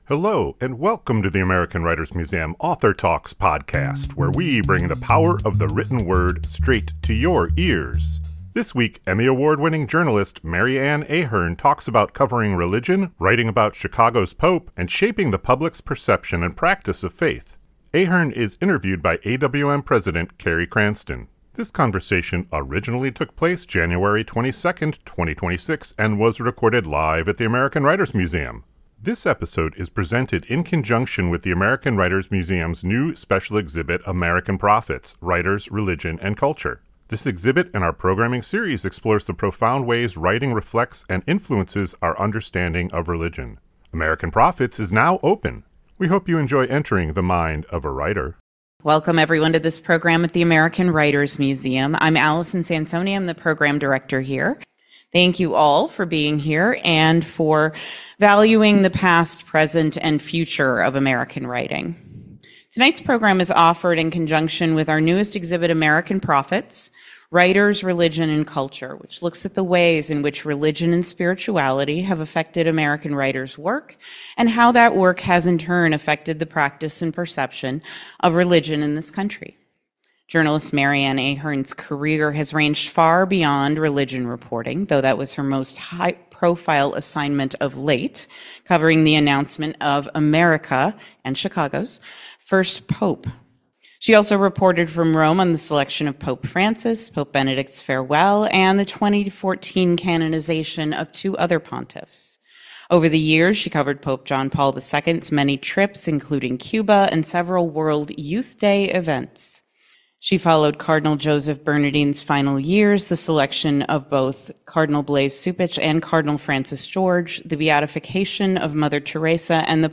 This conversation originally took place January 22, 2026 and was recorded live at the American Writers Museum.